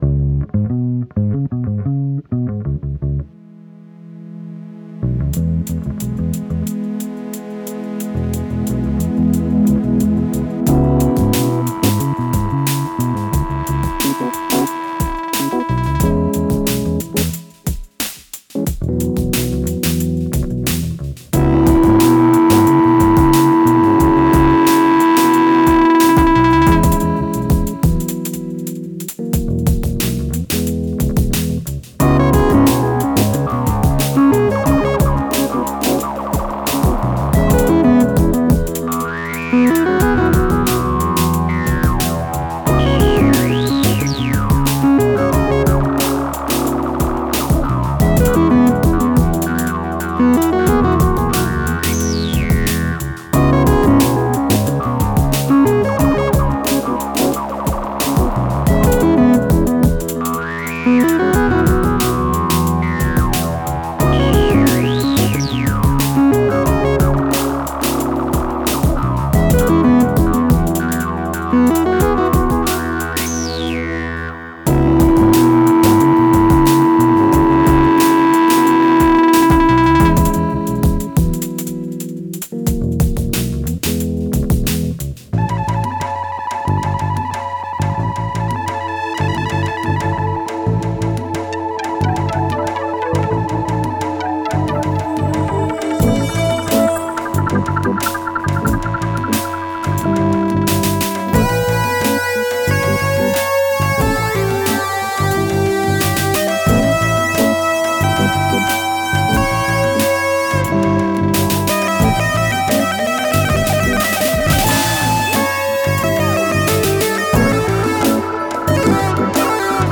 Snow Level Music